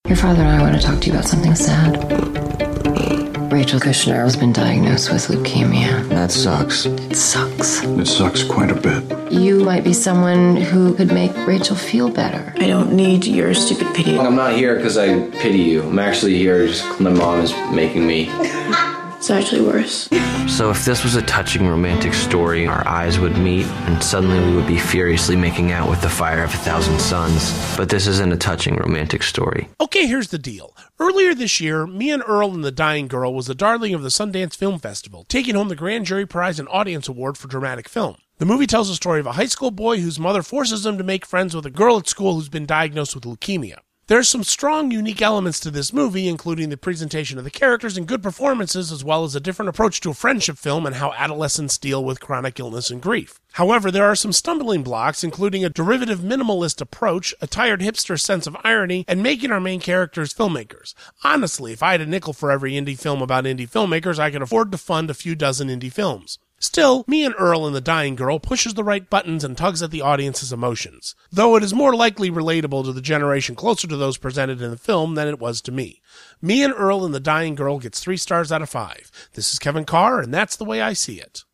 ‘Me and Earl and the Dying Girl’ Movie Review